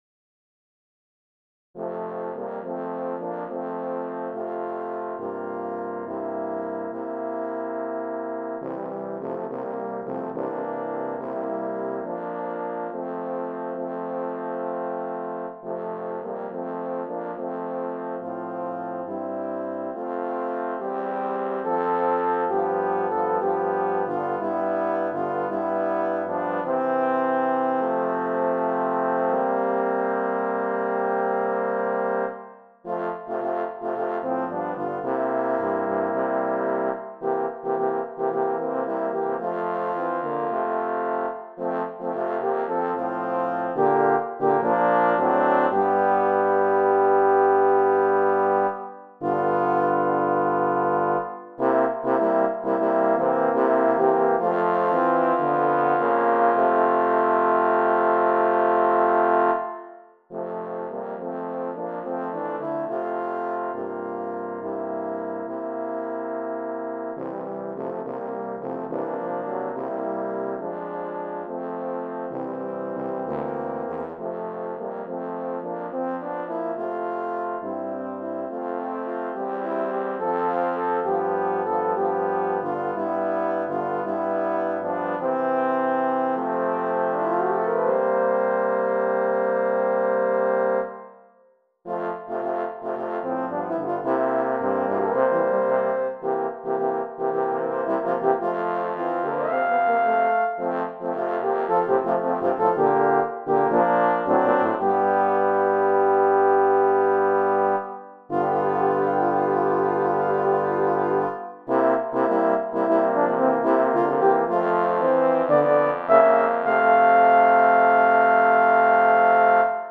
I have arranged it for horn quartet.